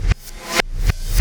Black Hole Beat 14.wav